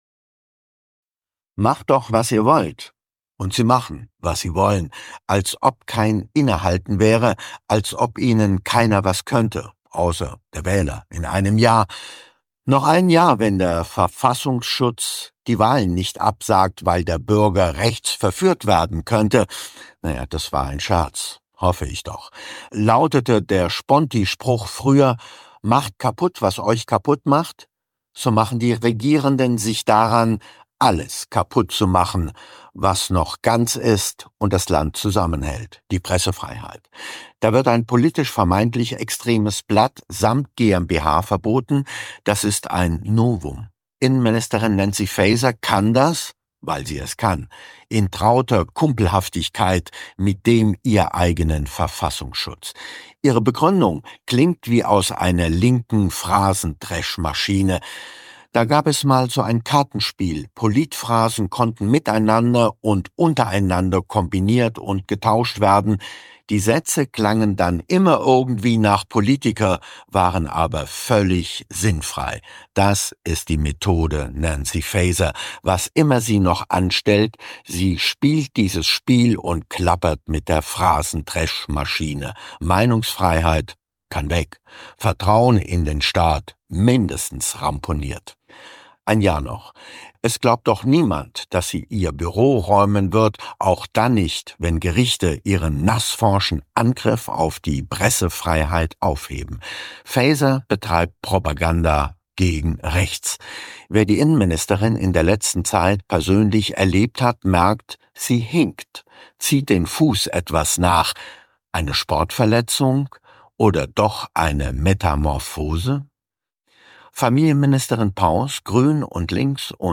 Mit dem AfD-Bundestagspolitiker Kay-Uwe Ziegler sprechen wir über die Kommunalpolitik. Können die Bürger auf diesem Wege wieder für Politik begeistert werden, beteiligen sie sich so mehr?